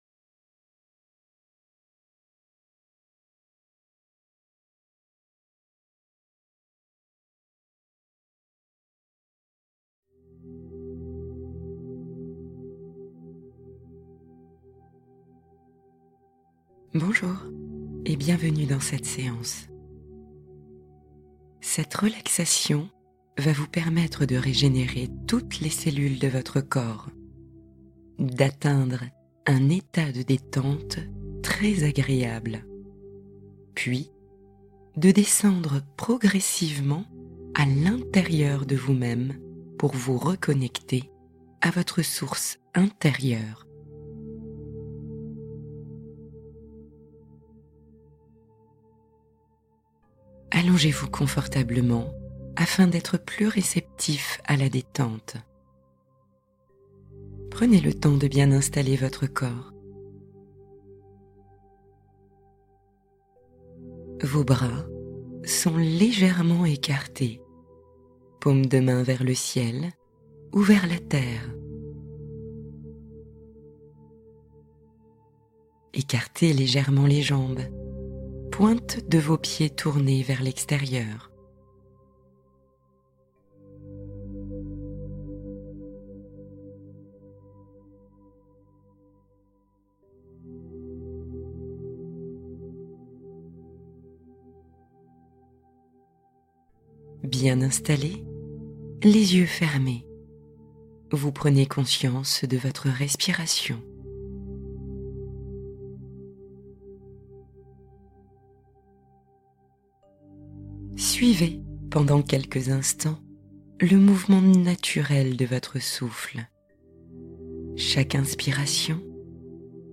Essence de Soi : Méditation de régénération et de reconnexion